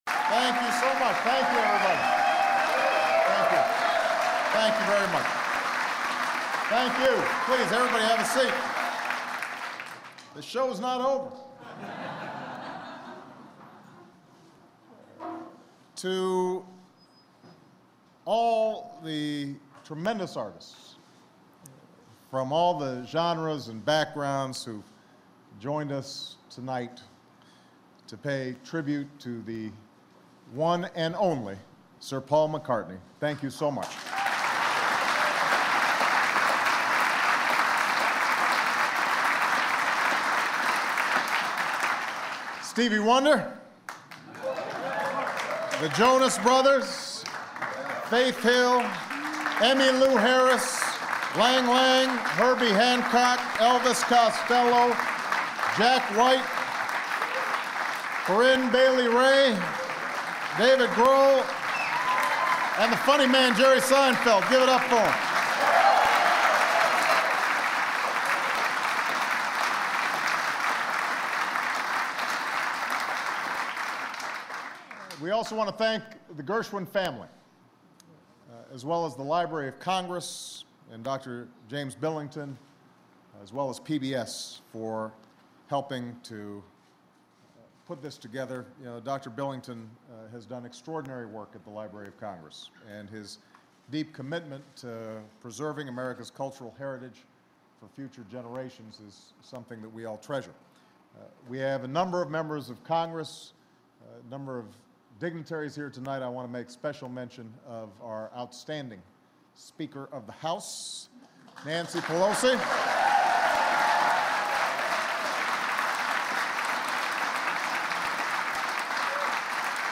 President Barack Obama presents Paul McCartney with the Library of Congress Gershwin Prize in a ceremony held in the East Room of the White House. Obama recounts McCartney’s storied career and his groundbreaking contributions to popular music. McCartney thanks the President and expresses his appreciation of and support for Obama.
Recorded in the White House East Room, June 2, 2010.